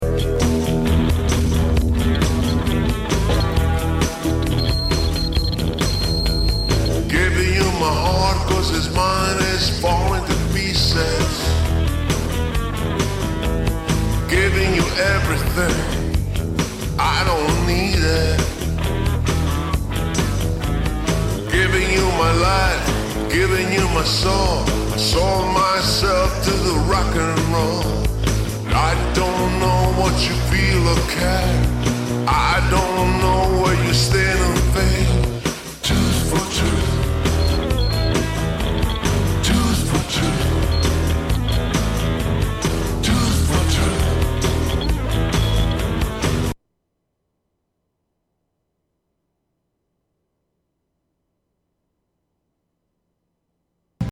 Vocals, Guitar, Bass, Keys